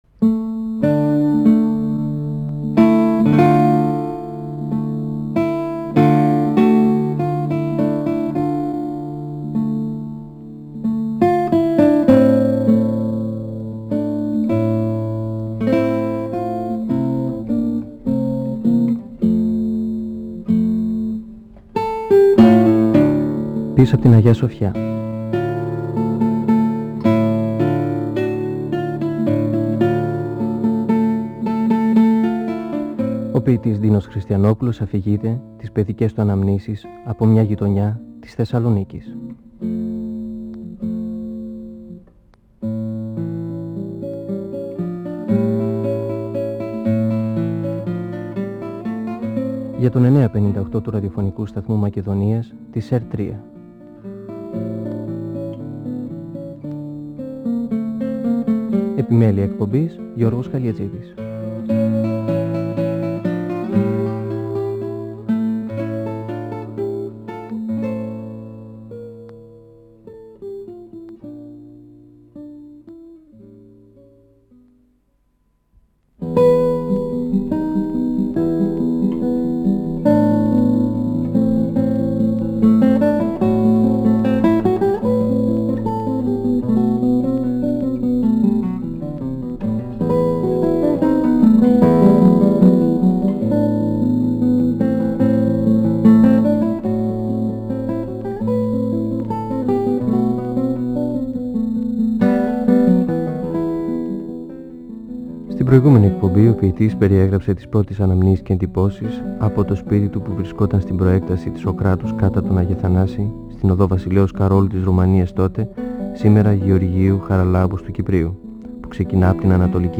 (Εκπομπή 5η) Ο ποιητής Ντίνος Χριστιανόπουλος (1931-2020) μιλά για τις αναμνήσεις του από μια παλιά γειτονιά της Θεσσαλονίκης, πίσω απ’ την Αγια-Σοφιά. Μιλά για το Ρουμανικό σχολείο, τα παιδιά των Βλάχων και τις αντιρρήσεις της μητέρας του να παίζει μαζί τους, την τιμωρία του, το παιγνίδι του με τις κούκλες, την Ιταλορουμανική προπαγάνδα στην κατοχή. Περιγράφει το σπίτι, που έμενε η οικογένειά του, και την αυλή, που έπαιζαν τα παιδιά και μαζεύονταν τα απογεύματα οι μεγαλύτεροι.